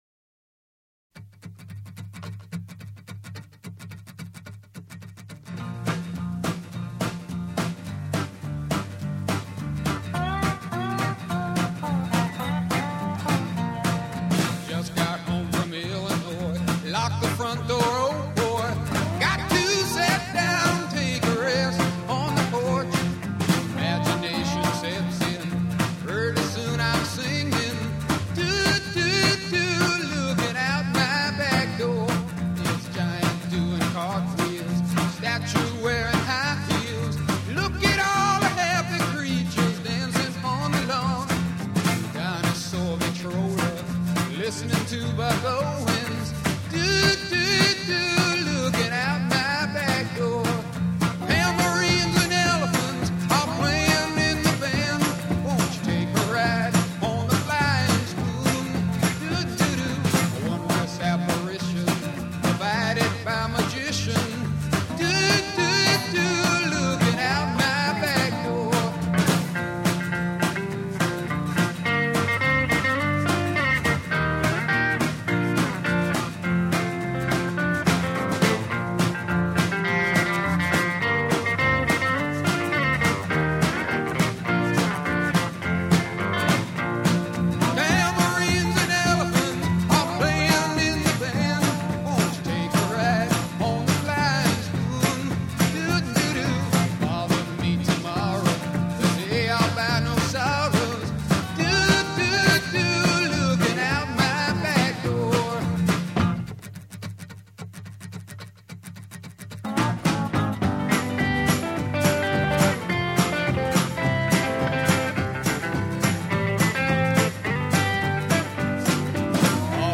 Рок музыка Рок Rock